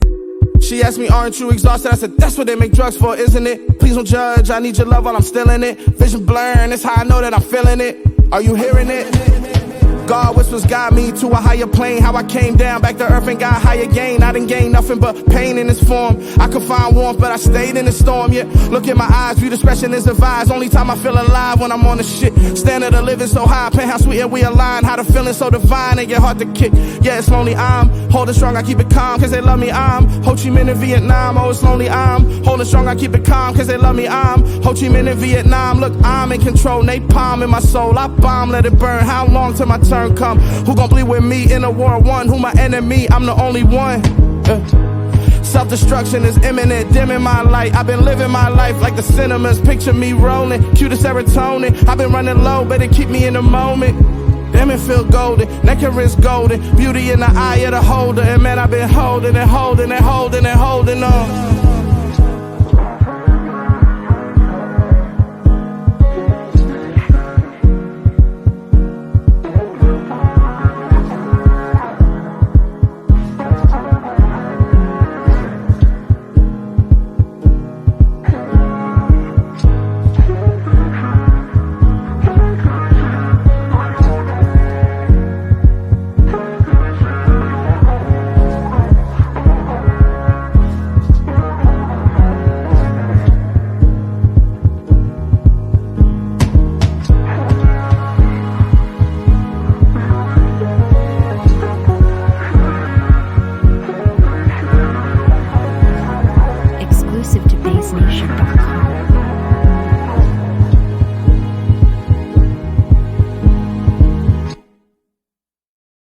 Renowned American rapper and performer